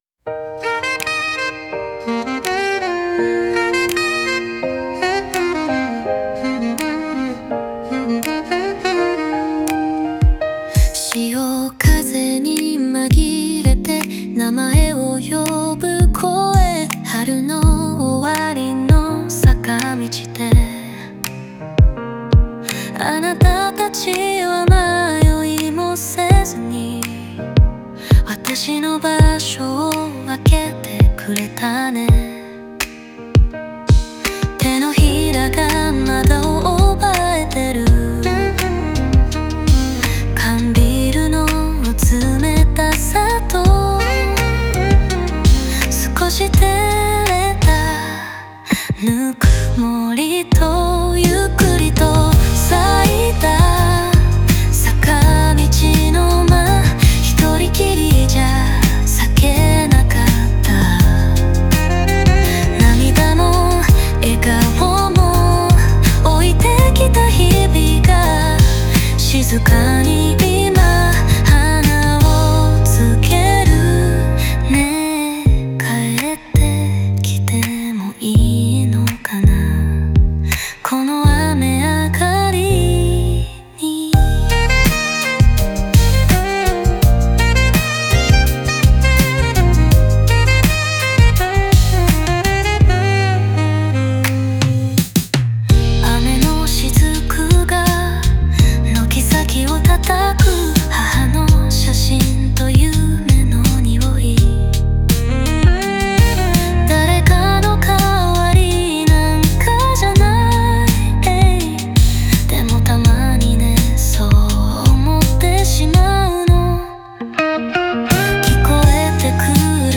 雨上がりの柔らかな光のように、胸の奥に新たな感情が芽吹いていく様子を、静かなR&Bのリズムに乗せて描きました。